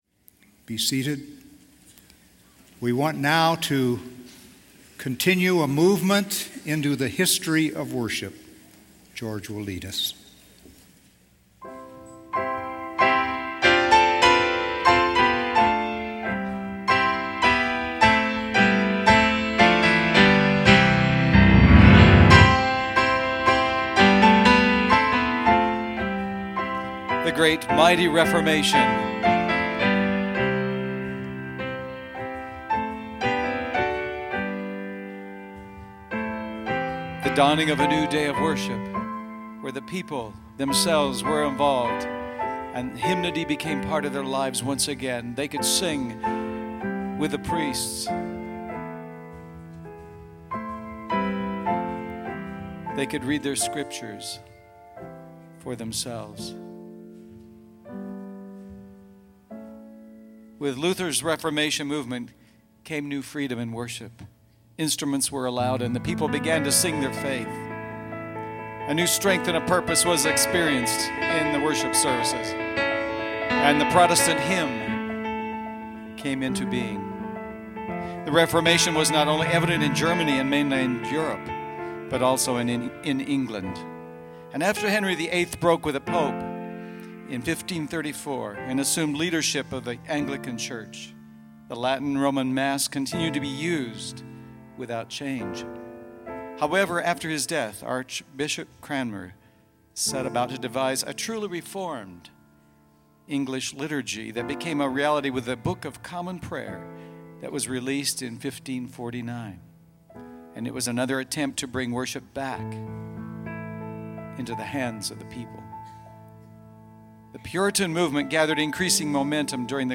Recorded at the Renovaré International Conference in Denver, CO.